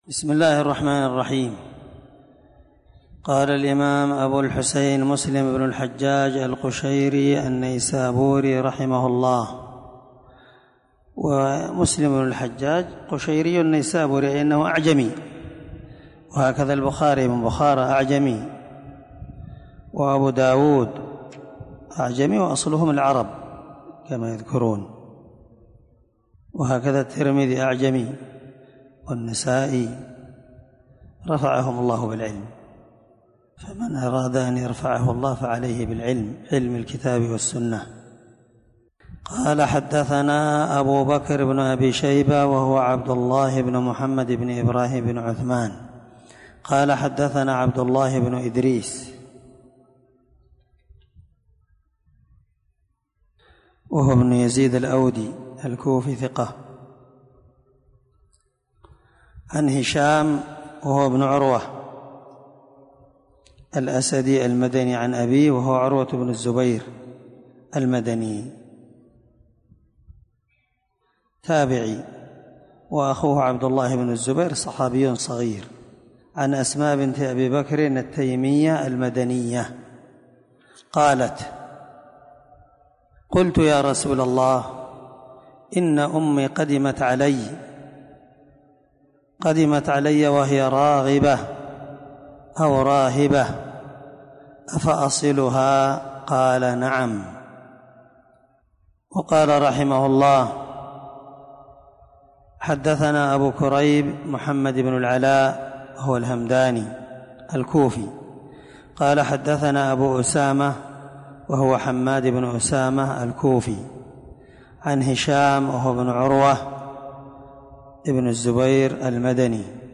612الدرس 20من شرح كتاب الزكاة حديث رقم(1003-1004) من صحيح مسلم
دار الحديث- المَحاوِلة- الصبيحة.